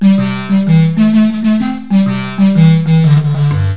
• Keys: 4" wide by 1" thick Sepele mahogany keys.
• resonators 4” pvc one-piece unit which is detachable.
Compact Baritone
baritone.wav